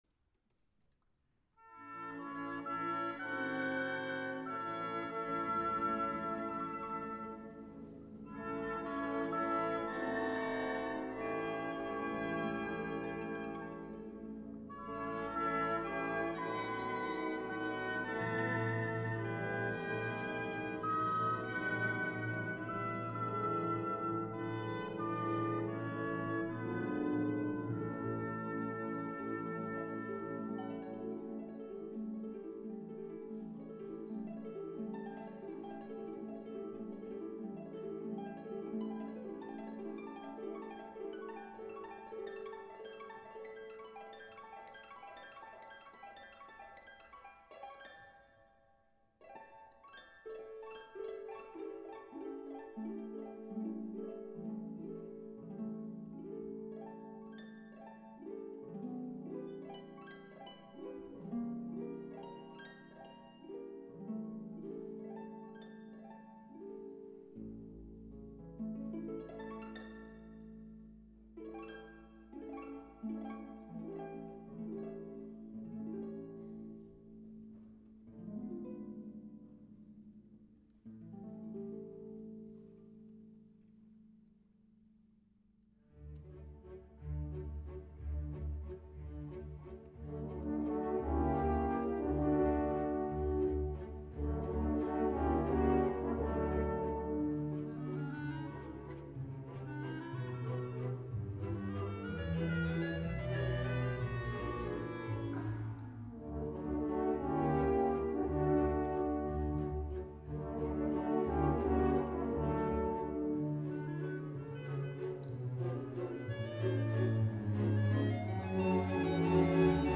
Listen to Audio Samples in Stereo!
An encore